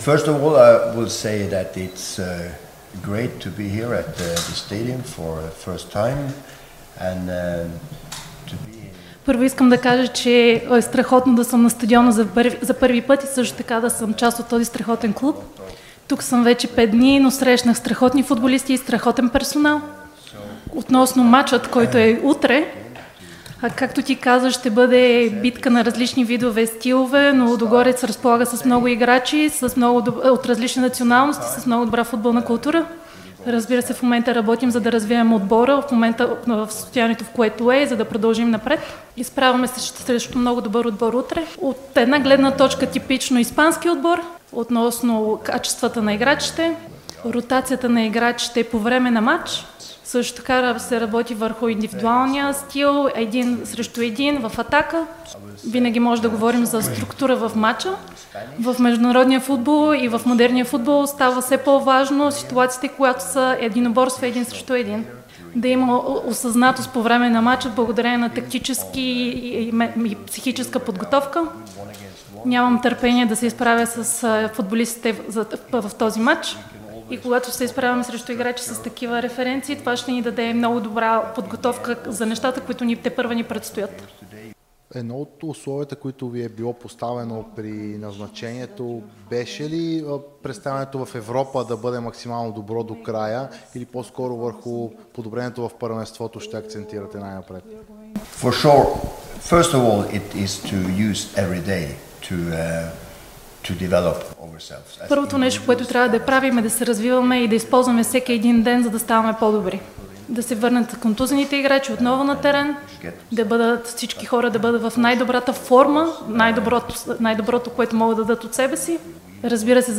Старши треньорът на Лудогорец – Пер-Матиас Хьогмо, даде пресконференция преди европейския си дебют за „зелените“ срещу Селта.